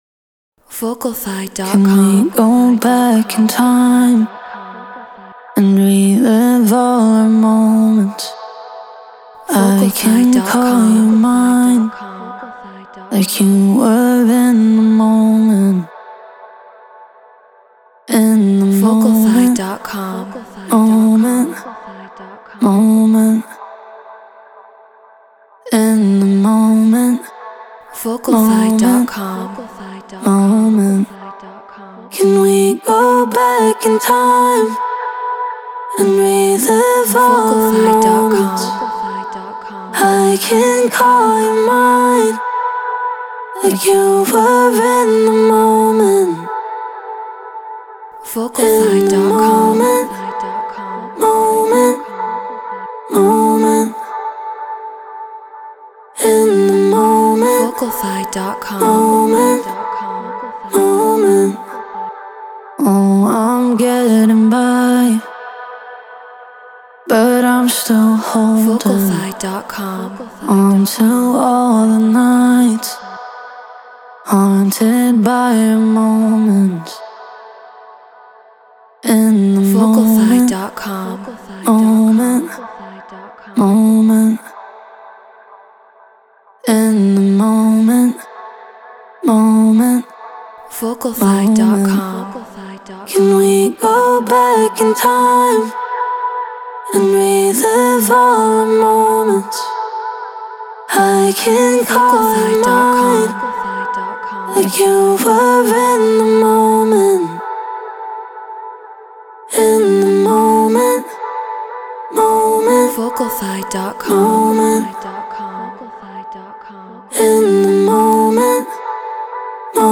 House 126 BPM Gmaj
Shure SM7B Apollo Solo Logic Pro Treated Room